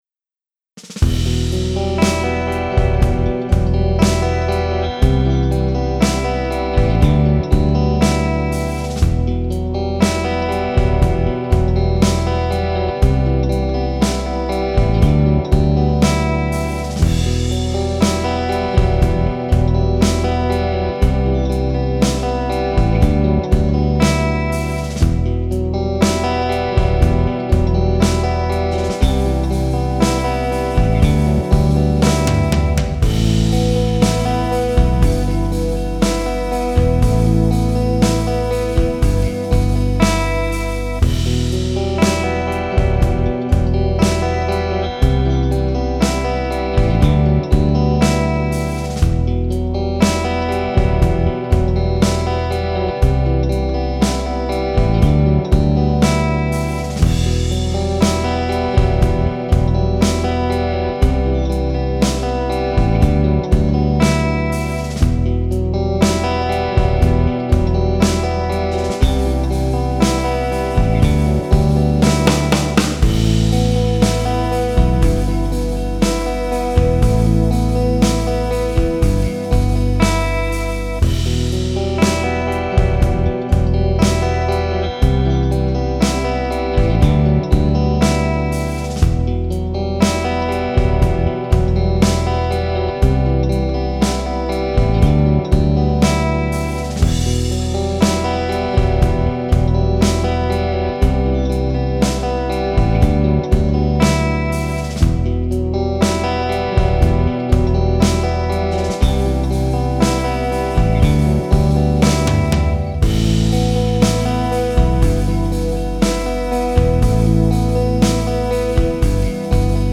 All tones were recorded with the Boss Katana Go via USB.
No Post Processing.